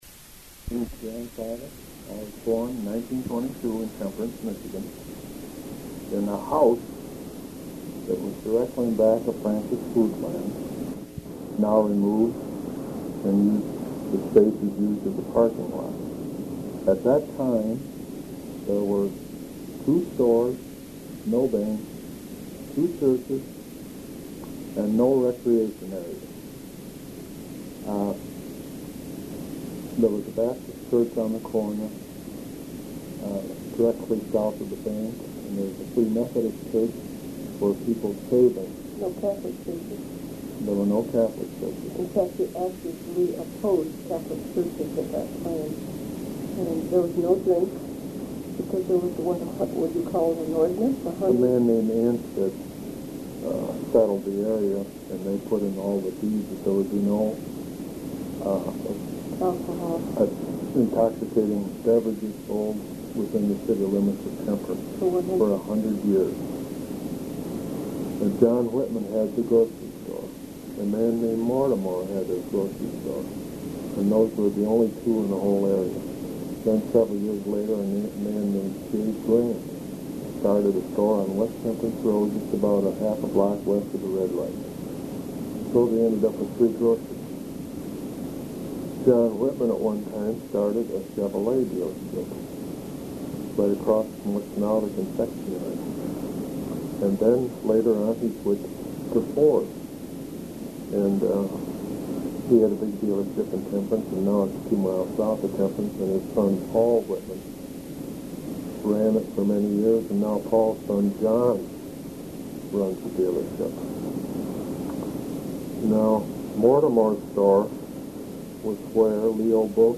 Interviews Sound recordings